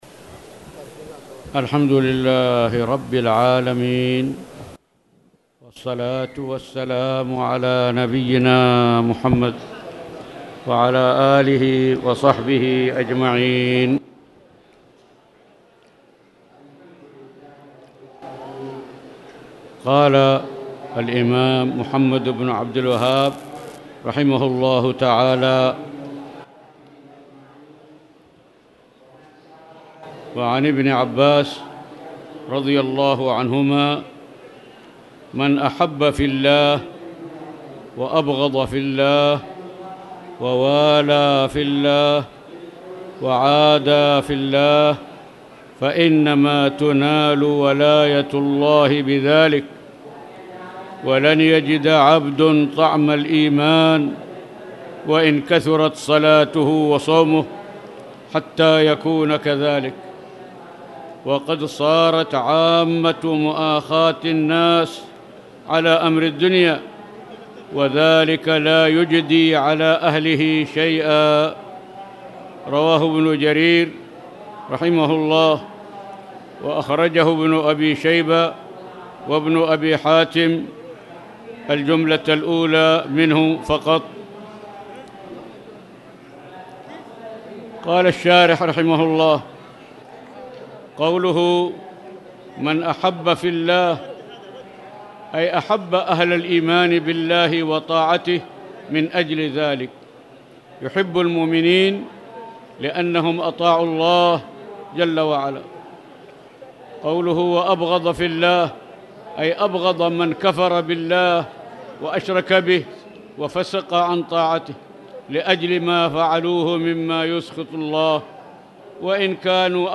تاريخ النشر ١٣ صفر ١٤٣٨ هـ المكان: المسجد الحرام الشيخ